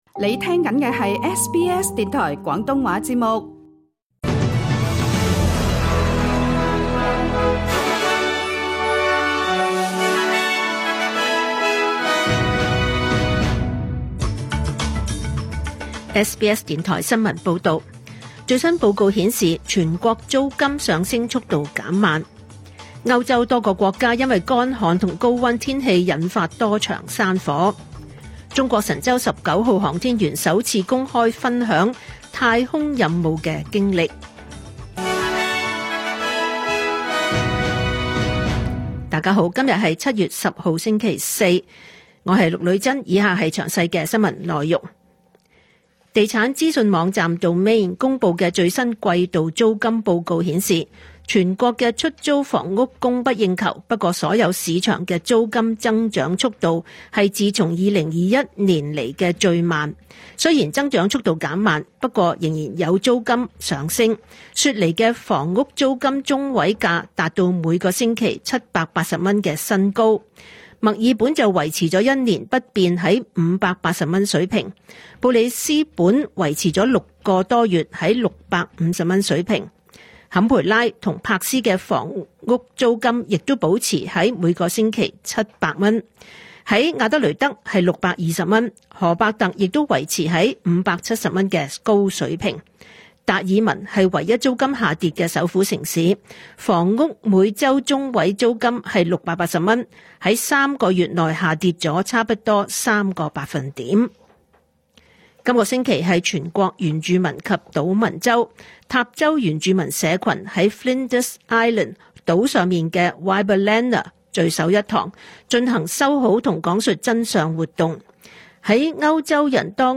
2025 年 7 月 10 日 SBS 廣東話節目詳盡早晨新聞報道。